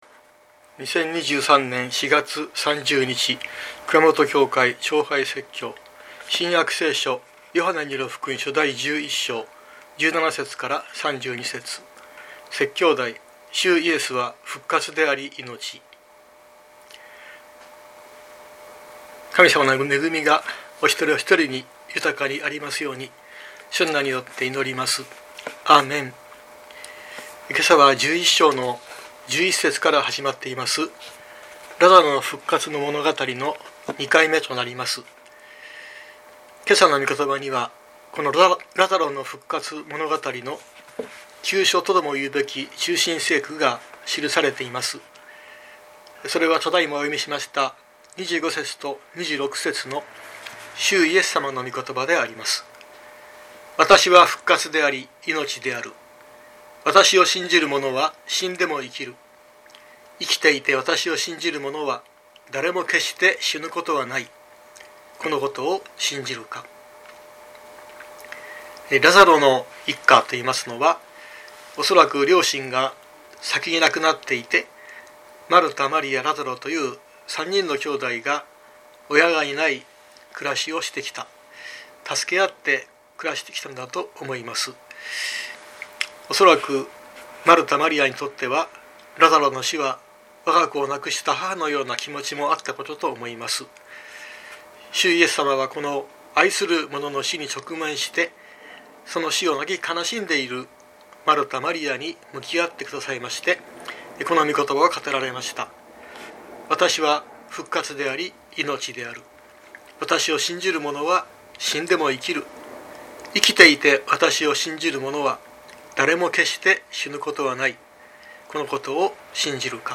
熊本教会。説教アーカイブ。